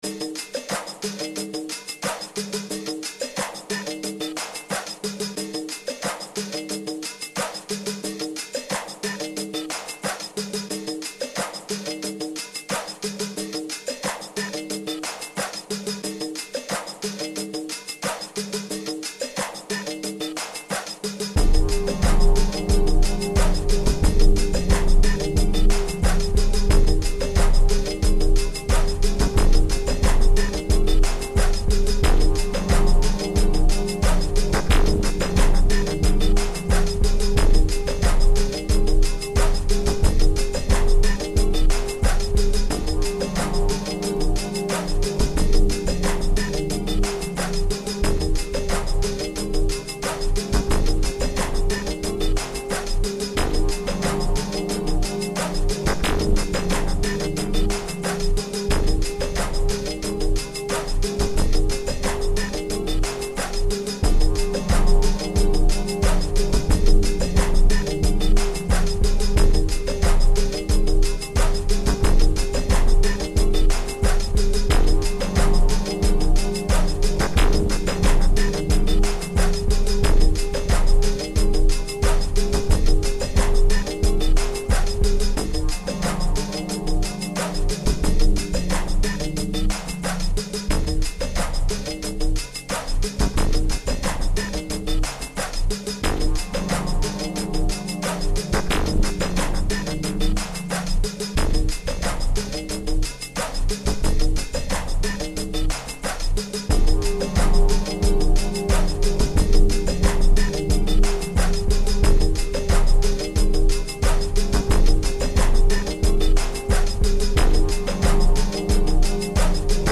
Lately I've been playing with some music software that allows you to make loop based music.
This ia mostly percussion, but it's got a nice rhythm to it.